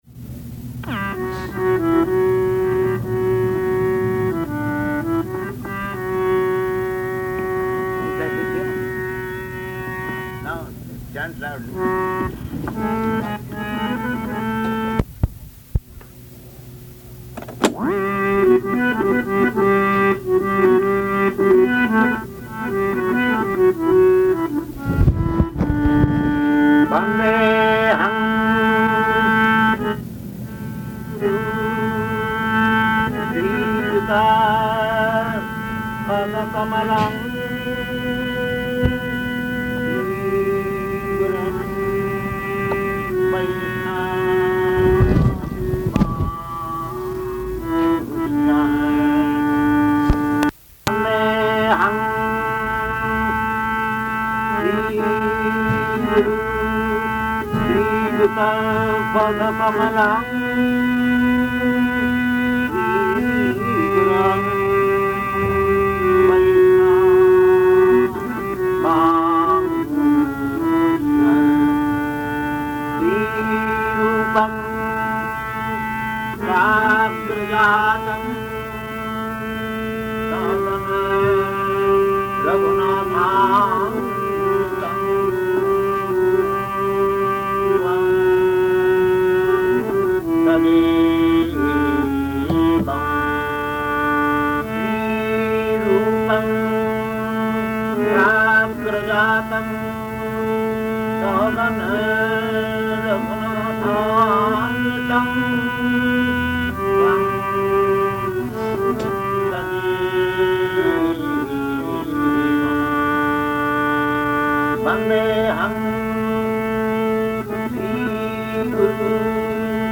Bhajan & Purport to the Maṅgalācaraṇa Prayers --:-- --:-- Type: Purport Dated: January 8th 1969 Location: Los Angeles Audio file: 690108PU-LOS_ANGELES.mp3 Prabhupāda: Now, chant loudly.